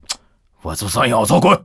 c02_11肉铺敲门_3.wav